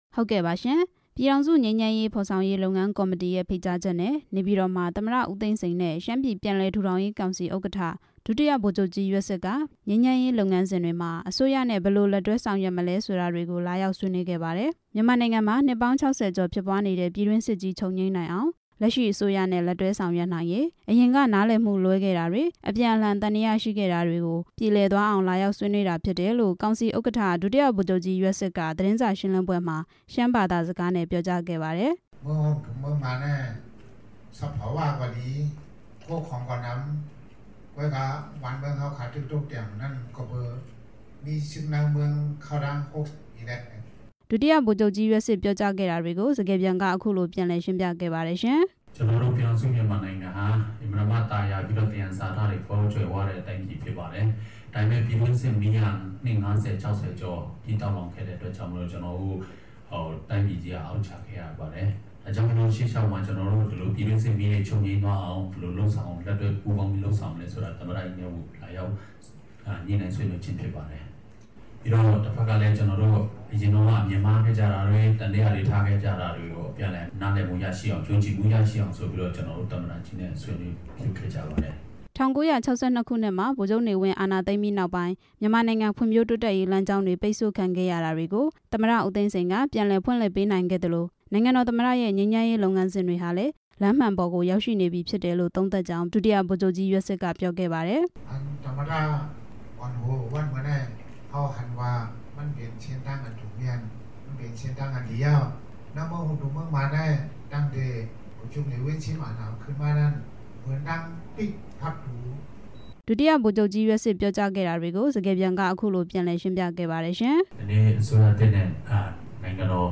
ဒုတိယဗိုလ်ချုပ်ကြီး ယွက်စစ်နဲ့ မေးမြန်းချက်
တွေ့ဆုံဆွေးနွေးချက်တွေကို ဒုတိယဗိုလ်ချုပ်ကြီး ယွက်စစ်က ရှမ်းဘာသာနဲ့ ပြောကြားပြီး စကားပြန်က အခုလို ရှင်းပြပါတယ်။